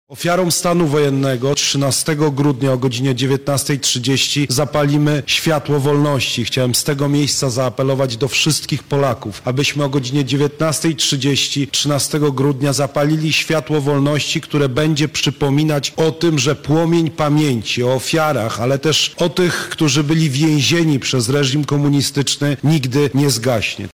40 lat temu wielu milionom Polaków złamano jakąkolwiek nadzieję na życie w wolnej i niepodległej ojczyźnie – mówi dr Karol Nawrocki, prezes IPN: